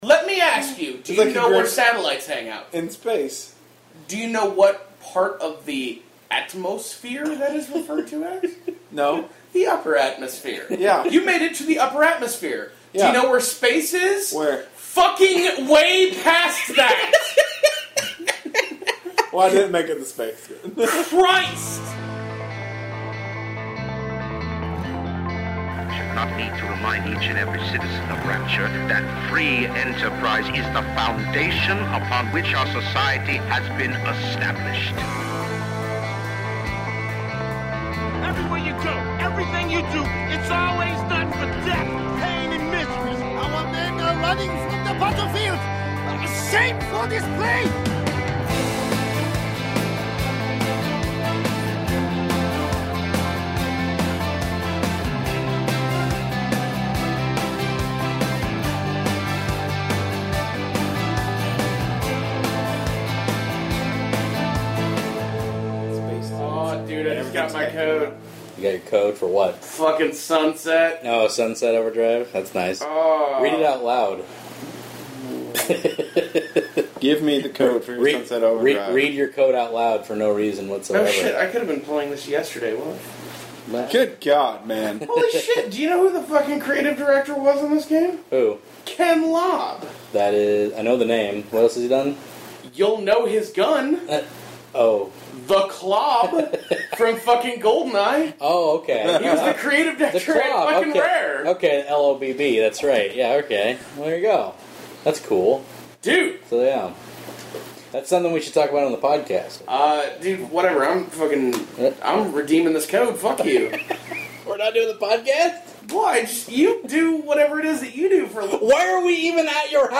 If you bare through the first 10 minutes of audio on this file you’ll notice we actually recorded a Podcast this week!